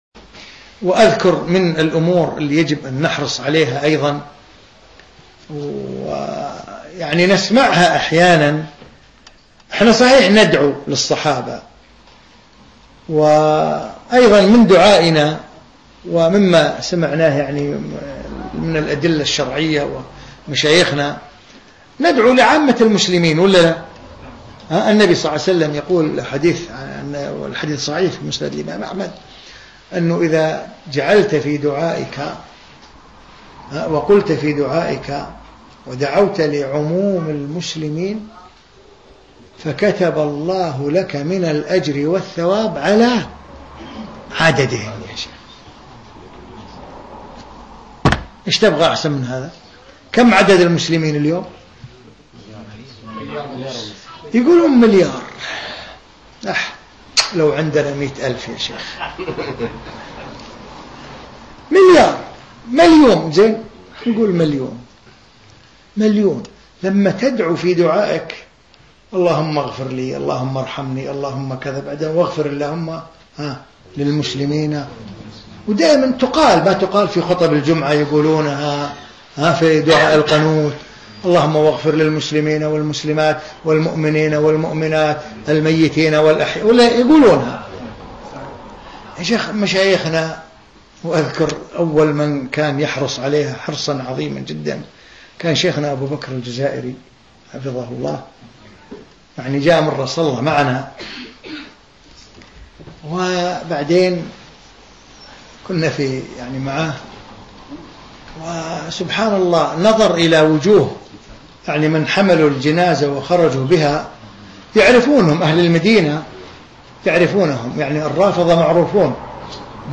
في مسجد الصحابة السلفي في مدينة برمنجهام البريطانية في 20 شوال 1435